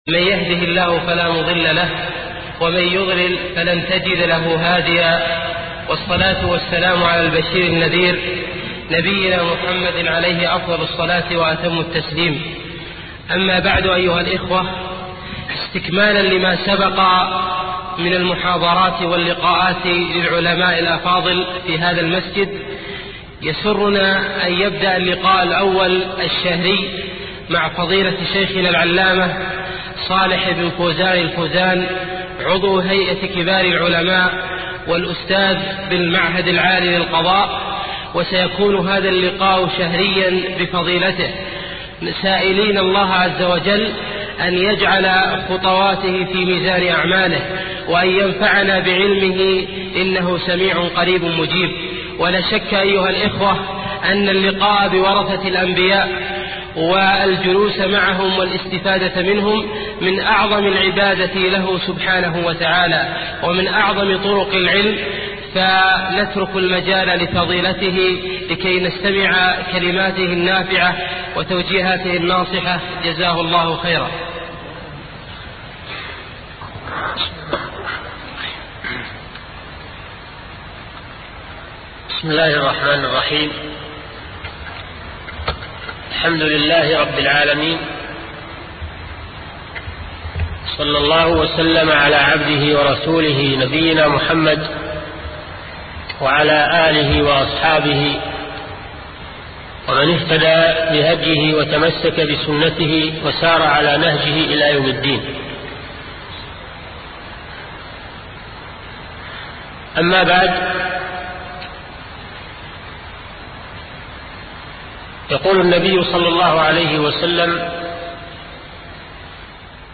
شبكة المعرفة الإسلامية | الدروس | الفقه الأكبر |صالح بن فوزان الفوزان